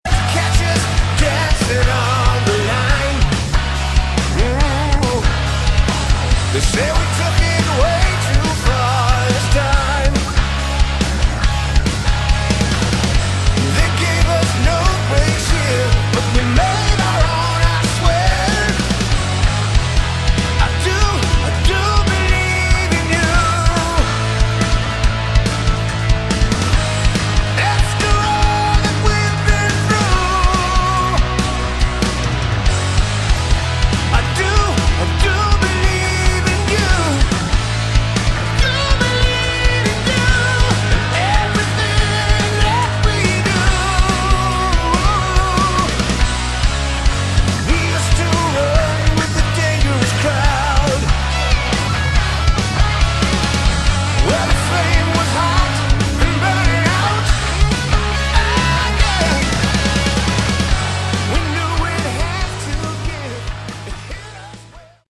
Category: Hard Rock
vocals
keyboards
bass
guitars
drums